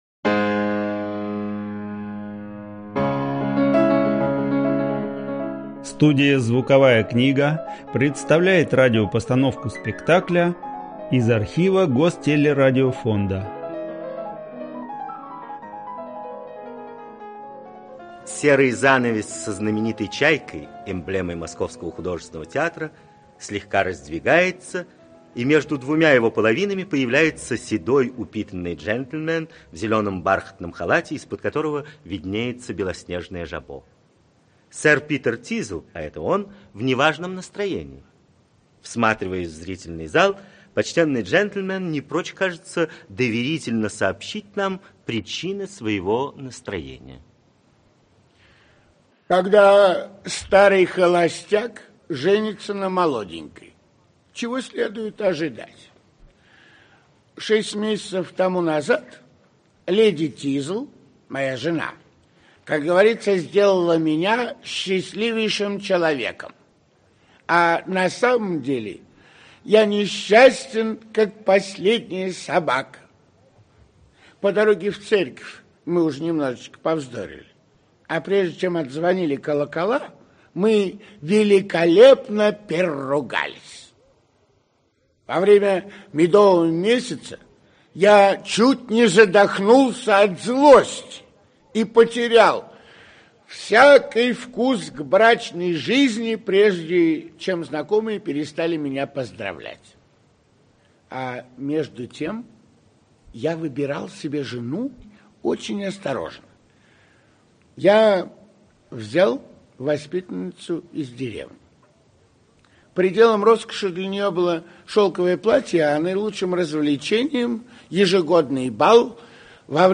Аудиокнига Школа злословия. Аудиоспектакль | Библиотека аудиокниг
Аудиоспектакль Автор Ричард Бринсли Шеридан Читает аудиокнигу Павел Массальский.